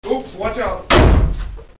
Voce in inglese che dice "Oops watch out !" e rumore di porta che viene chiusa con forza.